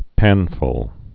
(pănfl)